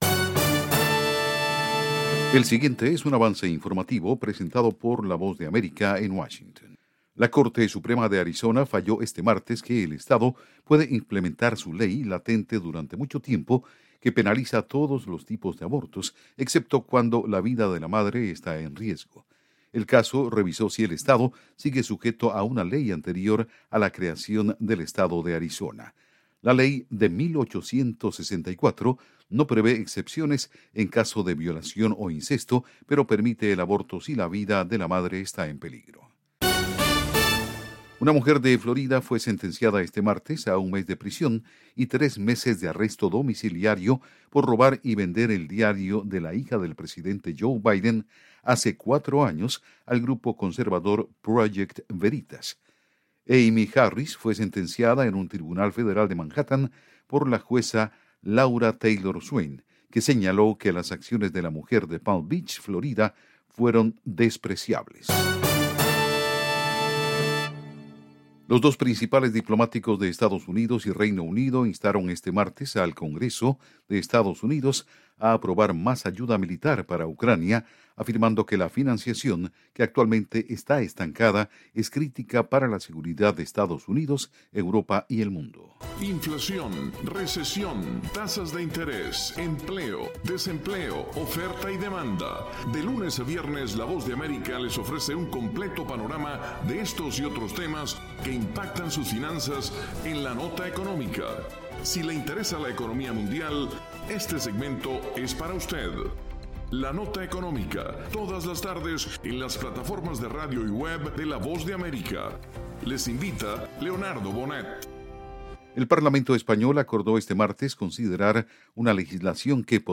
Avance Informativo 6:00 PM
El siguiente es un avance informative presentado por la Voz de America en Washington